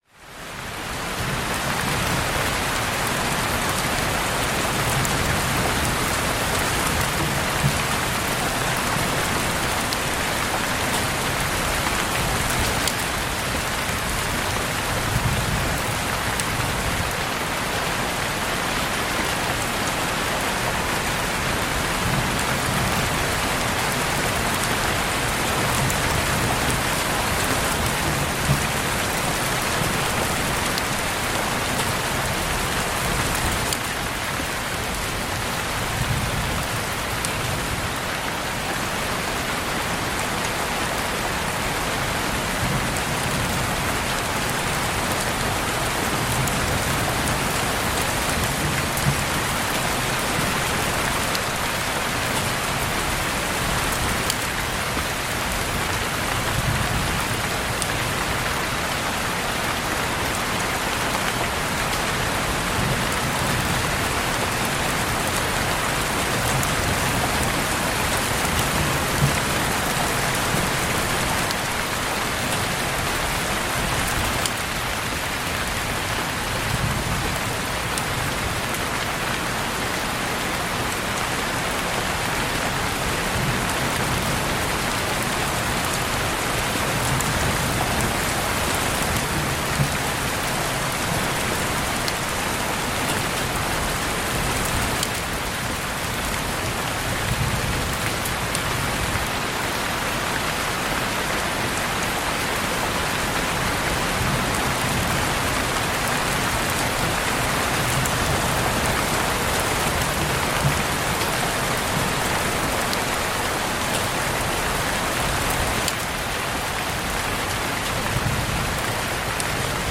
Pluie douce associée à une ambiance méditative favorisant un relâchement progressif